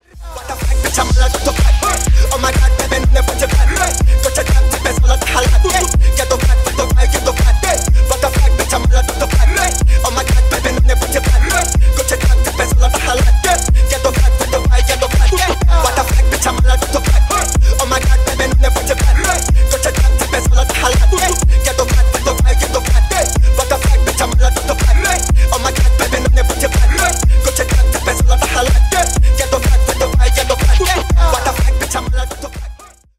Ремикс # Танцевальные # ритмичные